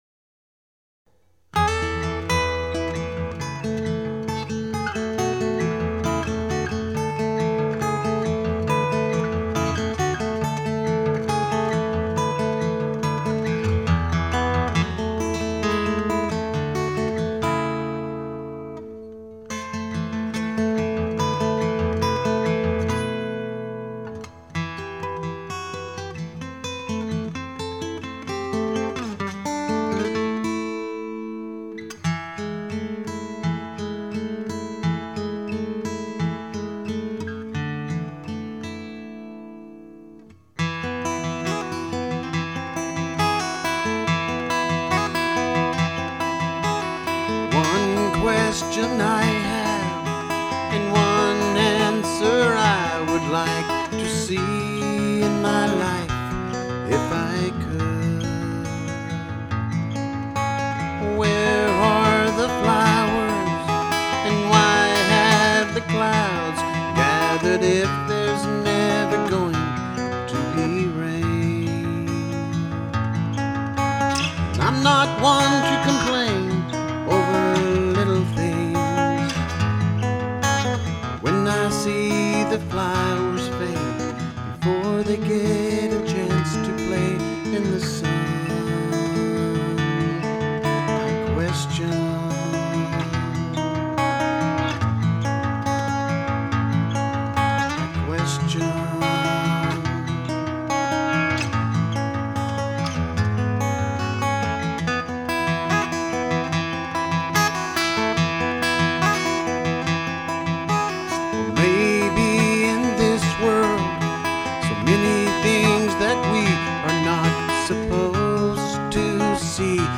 ORIGINAL ACOUSTIC SONGS
BASS